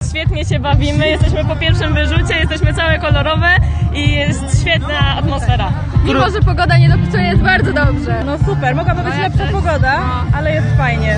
Uczestnicy Festiwalu Kolorów Łódź 2017